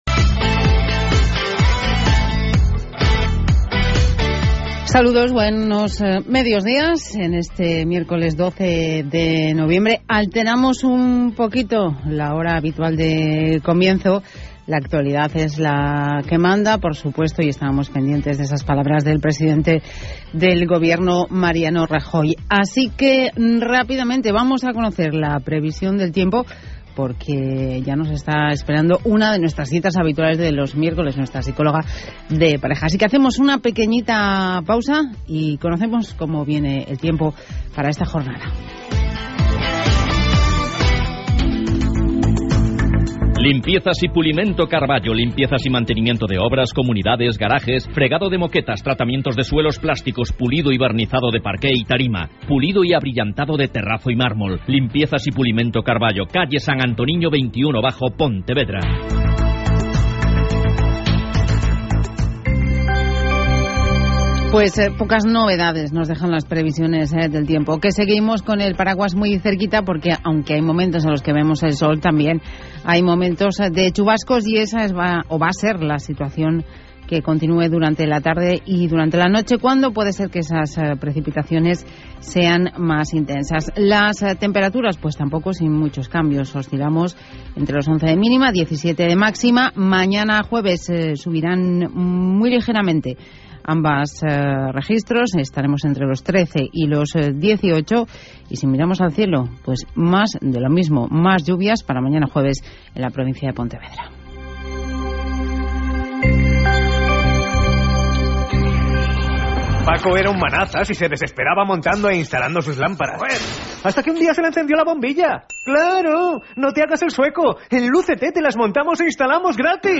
Radio: Podcast programa «La Mañana» COPE Pontevedra.
El audio es con mi intervención.